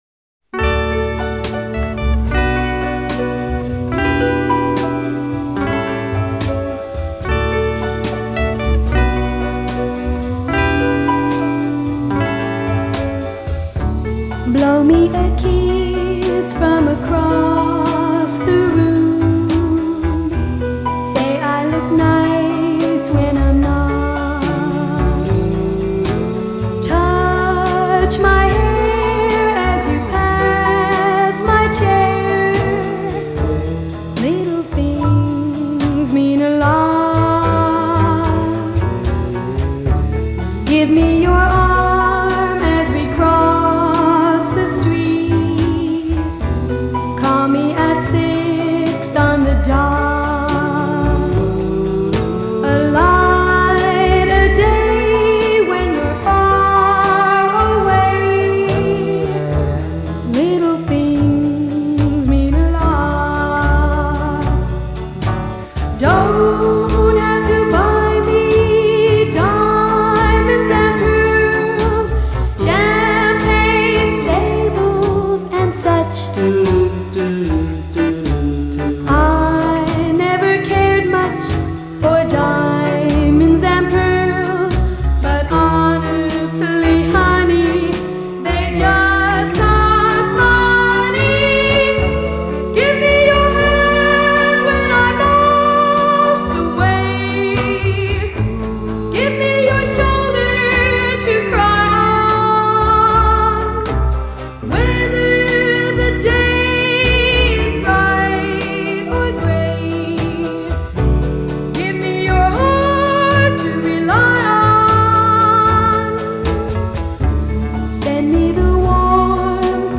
Жанр: Pop, Oldies, Doo-Woop
lead vocals